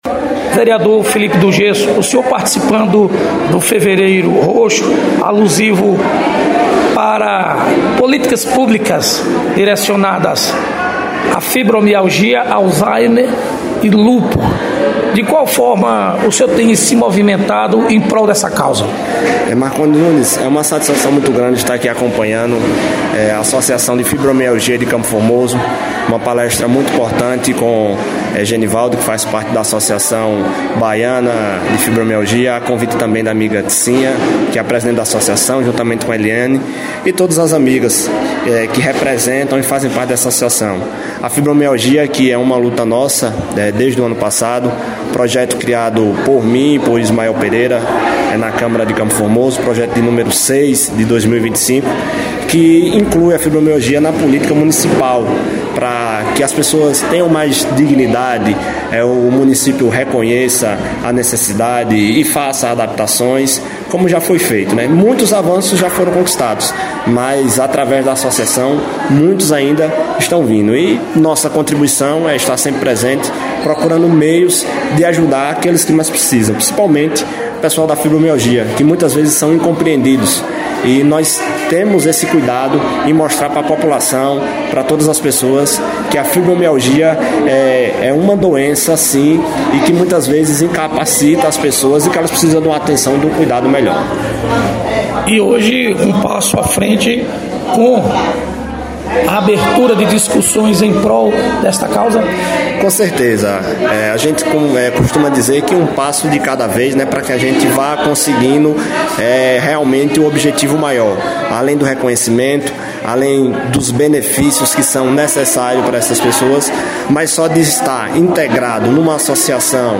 Vereador Felipe do Gesso – Comenta sobre o evento alusivo ao Fevereiro roxo em CFormoso